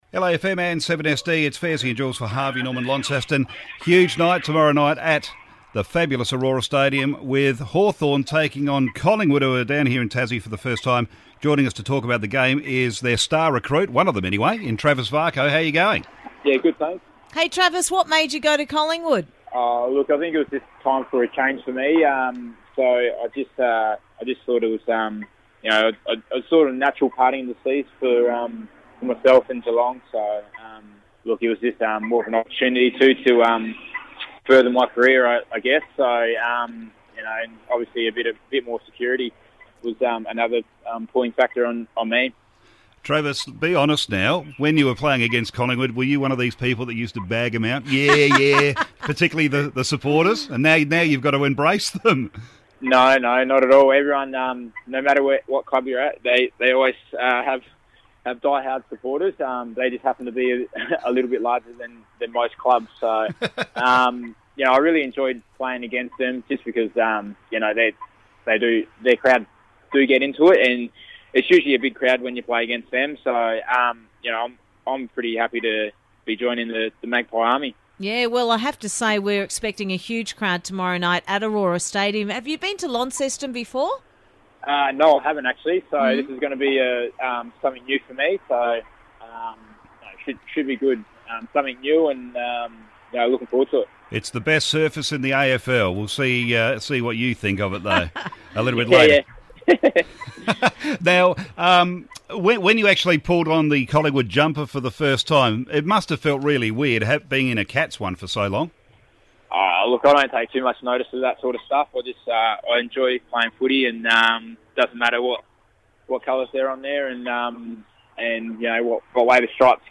Catch Collingwood recruit Travis Varcoe as he speaks to the team on LAFM, 89.3 LAFM Launceston.